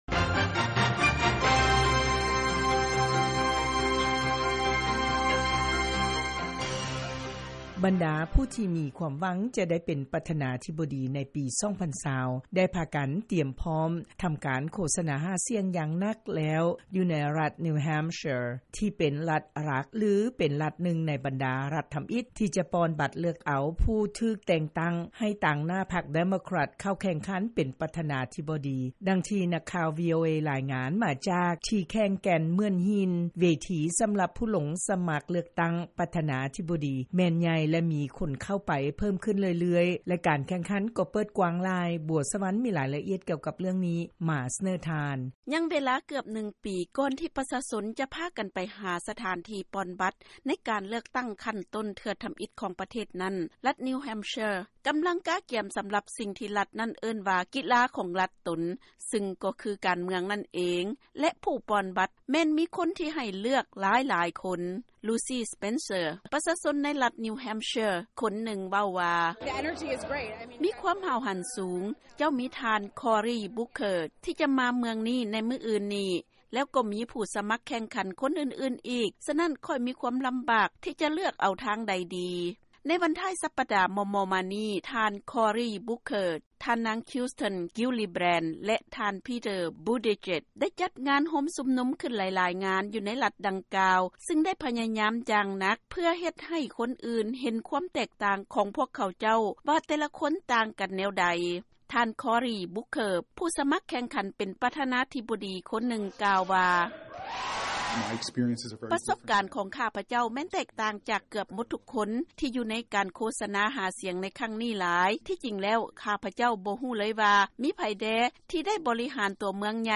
ເຊີນຟັງລາຍງານການຫາສຽງໃນລັດນີວແຮມເຊຍ, ລັດທຳອິດທີ່ເລືອກຕັ້ງເອົາຜູ້ຕາງໜ້າພັກໄປແຂ່ງຂັນ ເປັນປະທານາທິບໍດີ